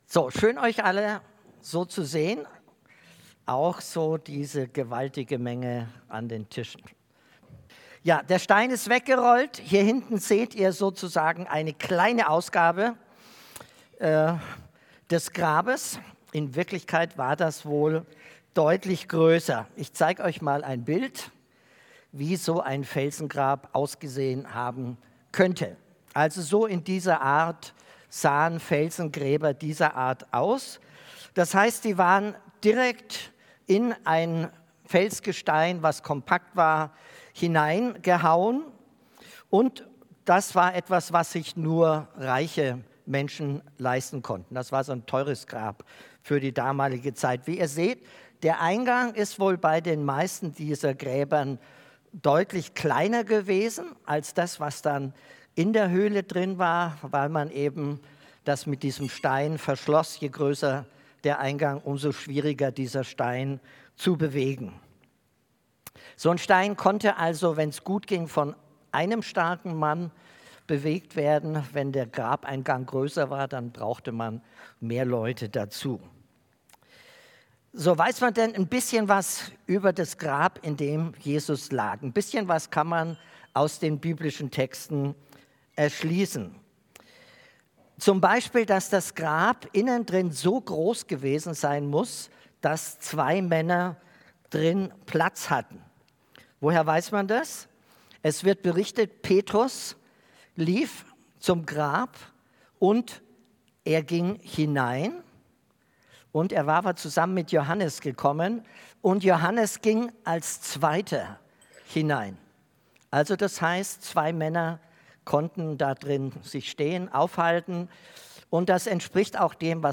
Gottesdienst mit Osterfrühstück
Predigt